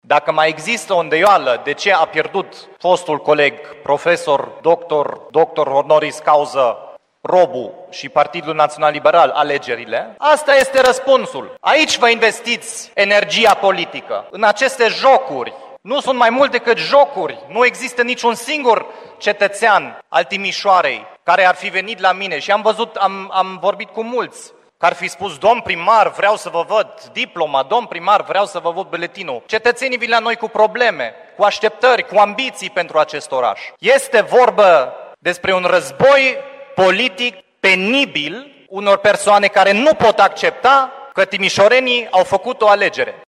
Şi primarul Dominic Friț a avut o reacție dură, reclamând un „război politic penibil”.